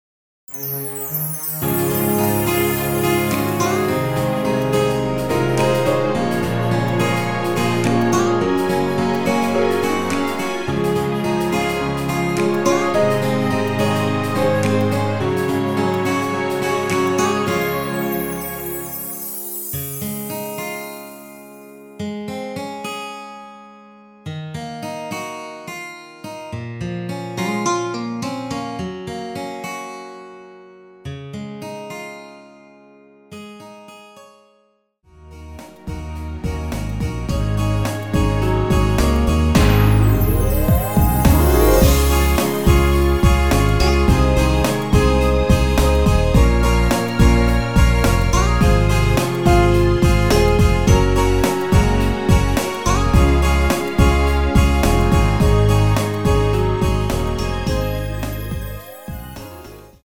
*(-5)내린 MR 입니다.
원곡이 페이드 아웃 곡이라 엔딩 만들었으니 미리듣기 참조 하세요
Db
앞부분30초, 뒷부분30초씩 편집해서 올려 드리고 있습니다.